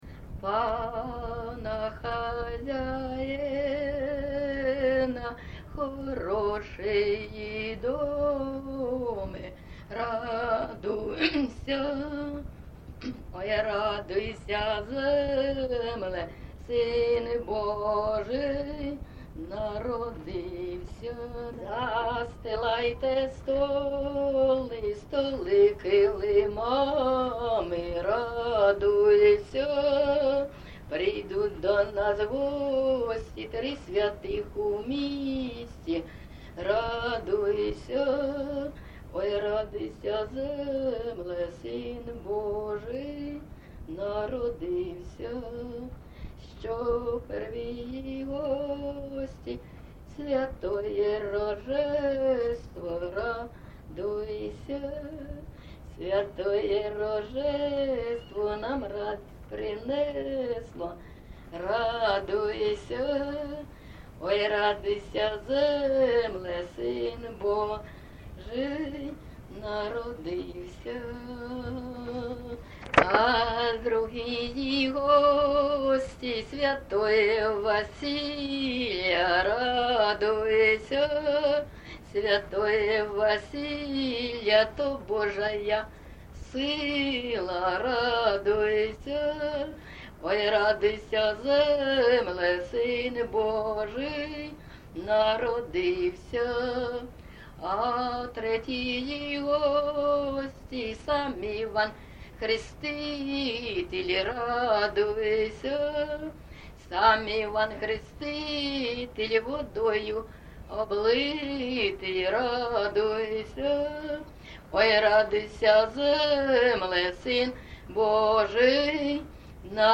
ЖанрКолядки
Місце записум. Маріуполь, Донецька обл., Україна, Північне Причорноморʼя